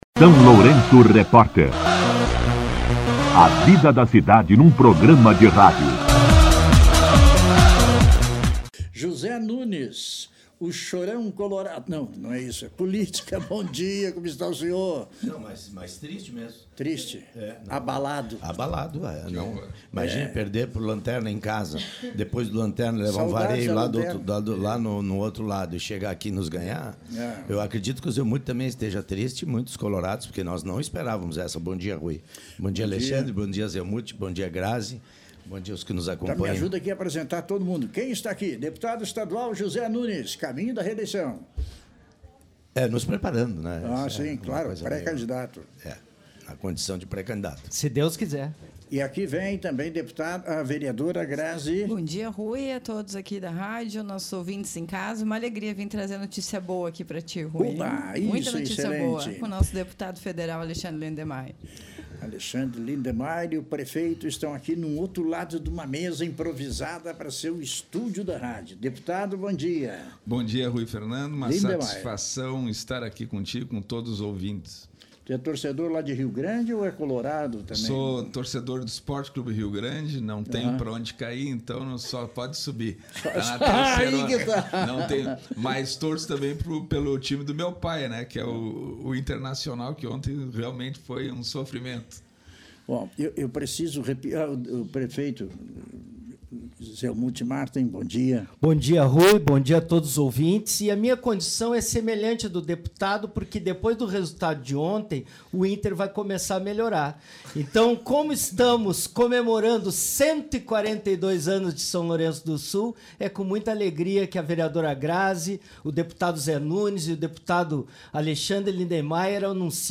Entrevista com O deputado federal Alexandre Lindemeyer, o deputado estadual Zé Nunes, o prefeito Zelmute Marten e a vereadora Grazi Vasques
O deputado federal Alexandre Lindemeyer, o deputado estadual Zé Nunes, o prefeito Zelmute Marten e a vereadora Grazi Vasques participaram, nesta segunda-feira (20), de entrevista no SLR RÁDIO para anunciar a destinação de R$ 1,65 milhão à Santa Casa de Misericórdia de São Lourenço do Sul.